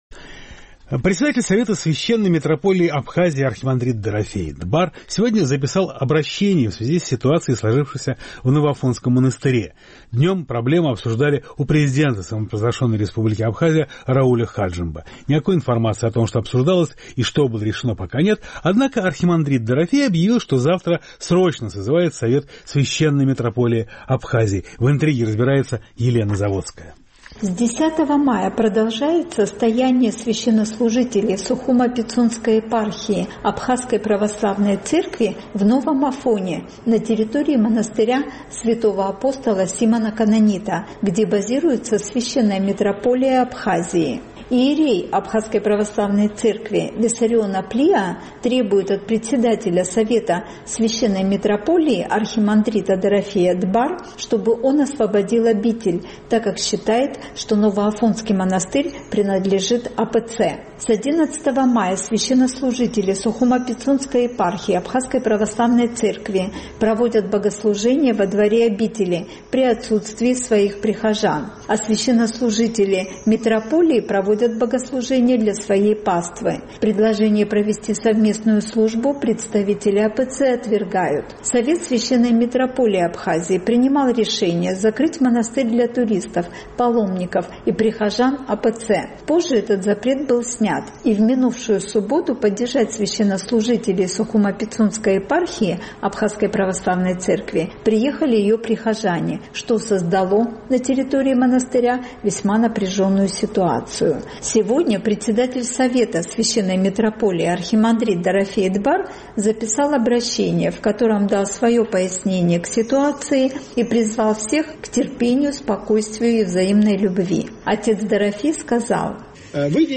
Сегодня председатель Совета СМА архимандрит Дорофей (Дбар) записал обращение, в котором дал свое пояснение к ситуации и призвал всех к терпению, спокойствию и взаимной любви.